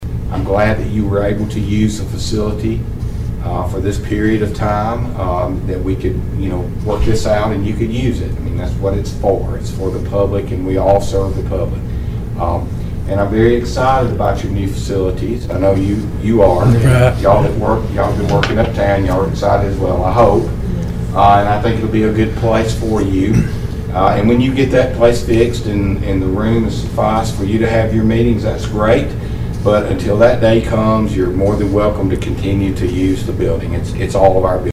During the regular meeting of the Cherokee County Board of Education Monday afternoon, the Board adopted a resolution of appreciation for the Cherokee County Commission.
County Commission Chairman, Tim Burgess was in attendance to receive the Resolution.